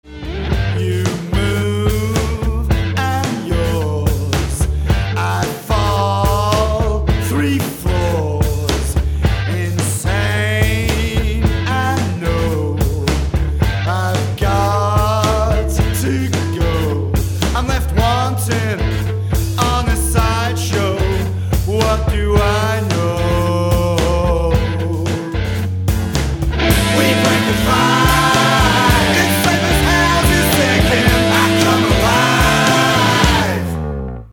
Third studio album